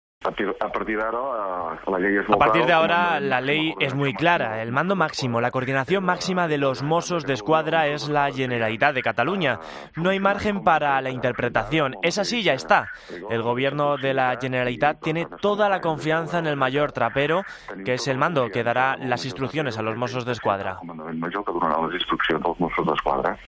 En una entrevista en Catalunya Ràdio, Turull ha dicho que los Mossos seguirán funcionando como han hecho hasta ahora, "con el mismo rigor y la misma proximidad" .